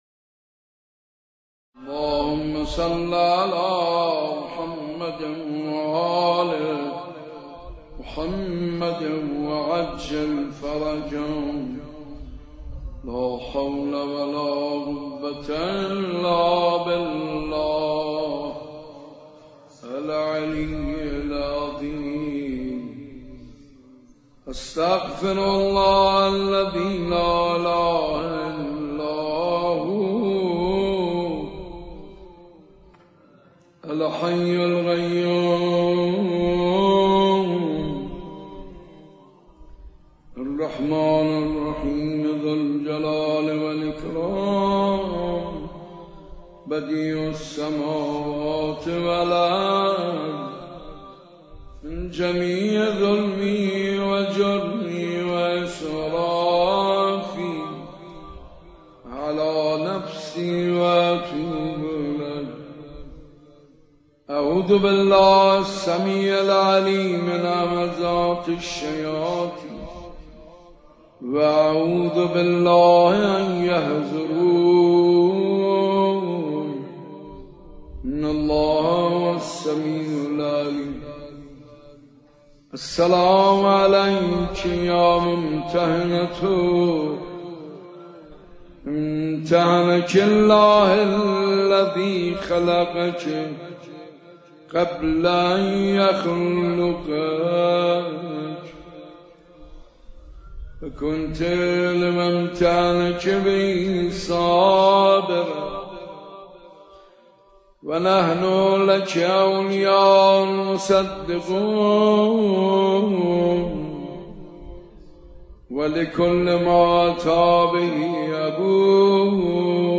مداحی جدید حاج منصور ارضی شب اول فاطمیه 1442 چهارشنبه 3 دی 1399 مسجد ارک تهران